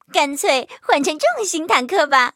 M2中坦中破修理语音.OGG